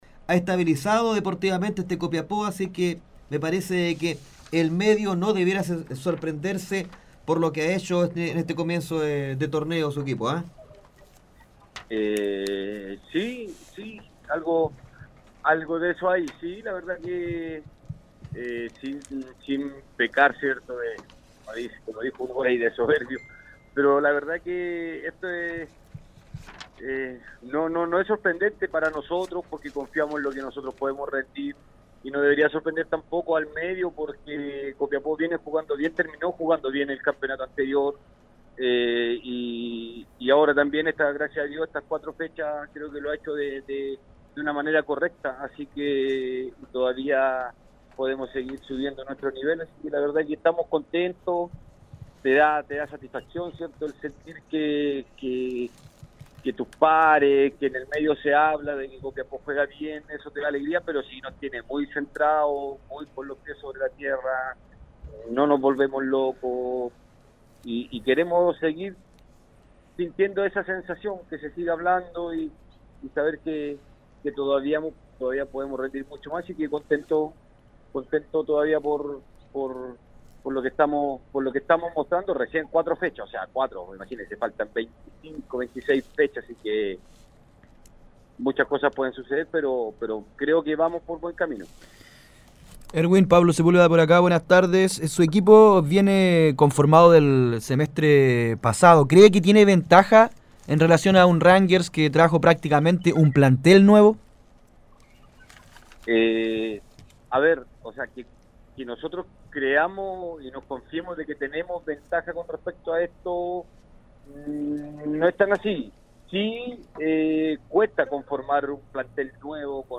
Cree que en el actual plantel hay profesionales listos para dar ese salto. Así lo expresó en conversación con Gigante Deportivo.